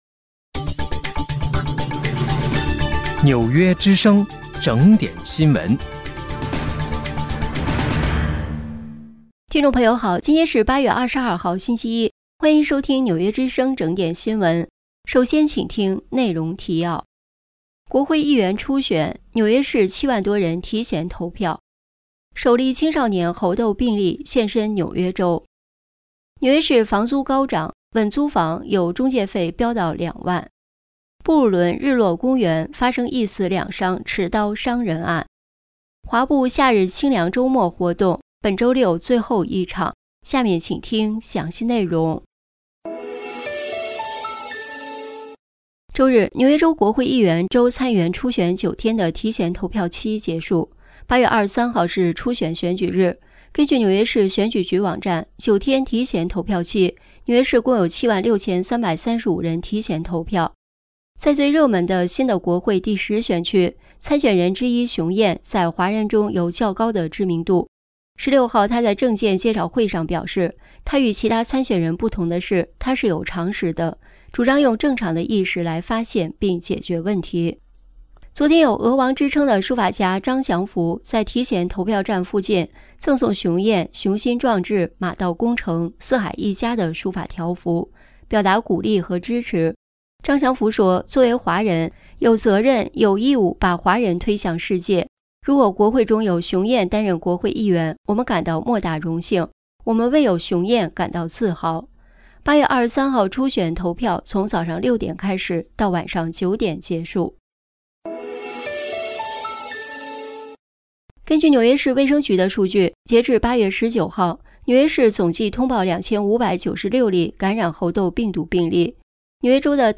8月22日（星期一）纽约整点新闻